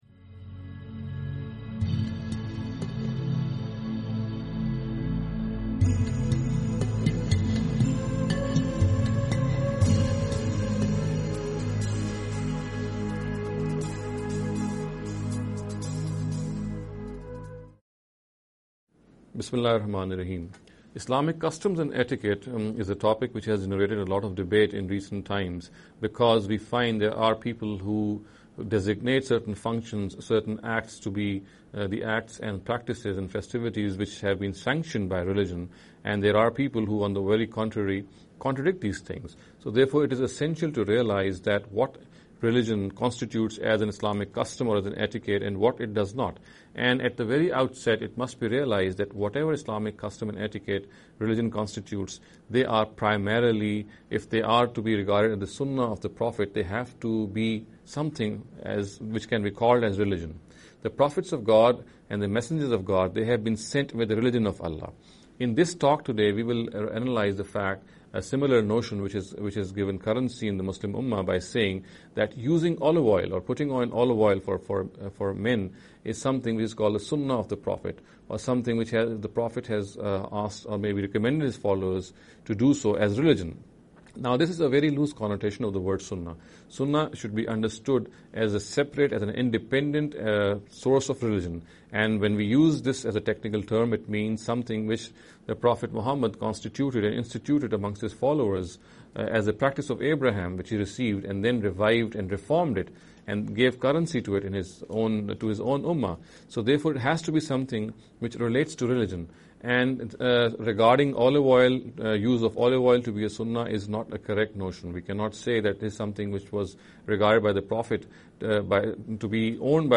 This lecture series will deal with some misconception regarding the Islamic Customs & Etiquette.